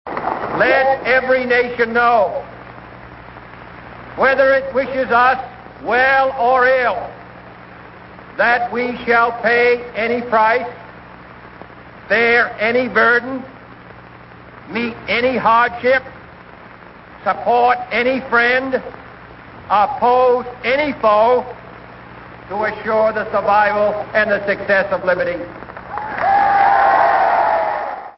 The phrase New Frontier appears inside Senator John F. Kennedy’s speech of 15 July 1960 in Los Angeles, CA, wherein he accepted the nomination of the Democratic Party for POTUS.
Here are outtakes of the speech: